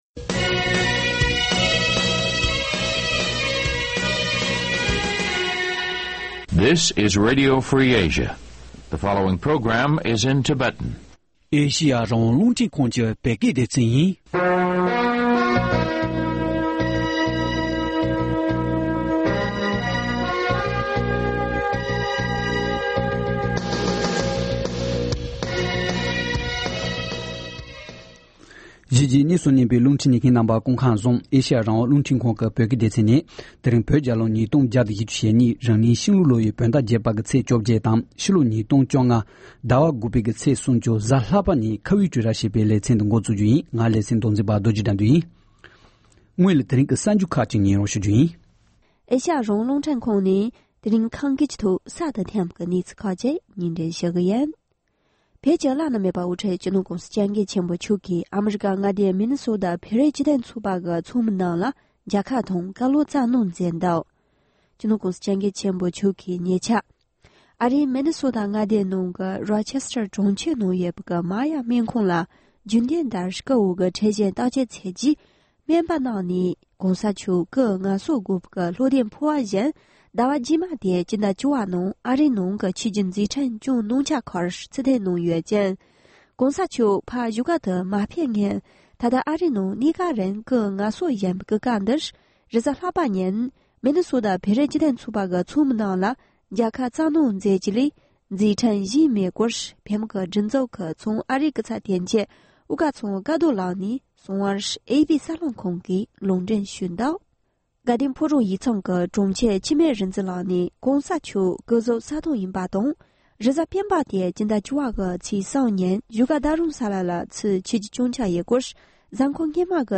སྐབས་༡༦པའི་བོད་མི་མང་སྤྱི་འཐུས་ཀྱི་མདོ་སྟོད་ཆོལ་ཁའི་འོས་མི་གསར་པ་ཁག་ཅིག་དང་ལྷན་དུ་གླེང་མོལ།